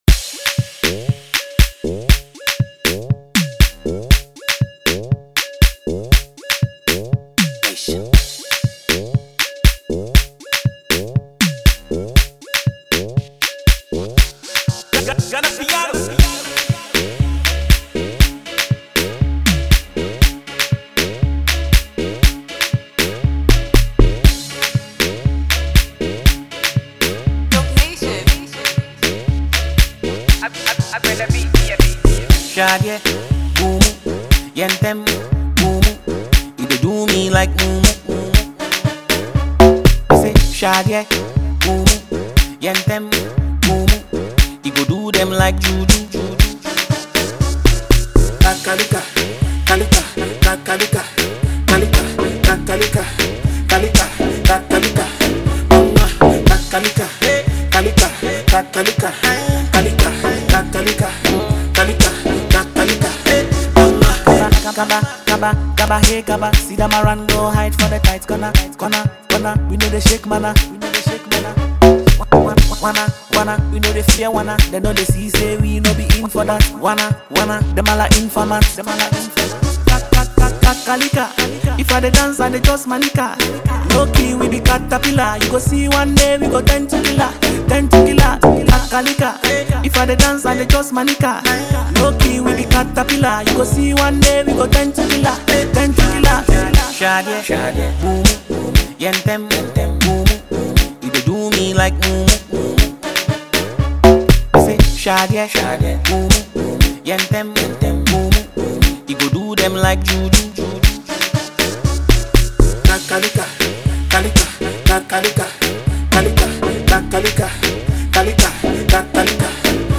mixing Afro-inspired beats with current sounds